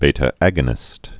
(bātə-ăgə-nĭst, bē-)